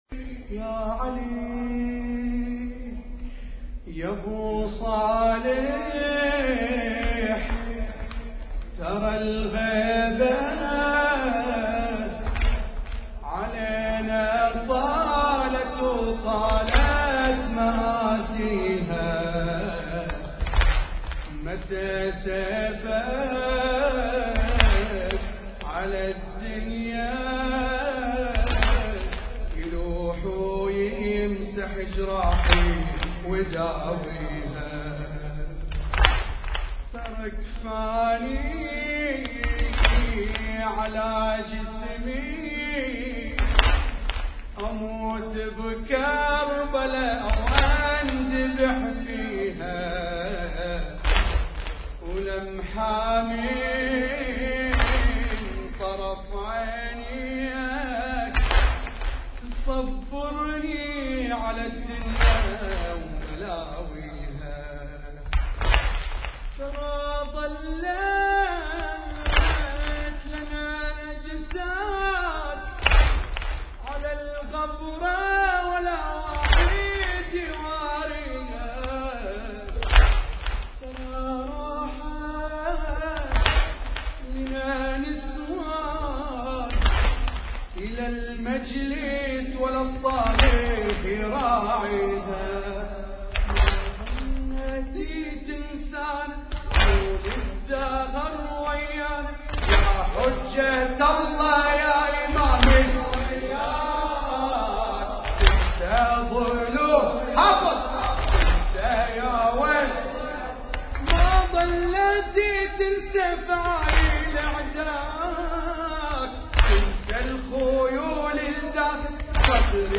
موكب عزاء الدراز الموحد ١٤٣٩ هـ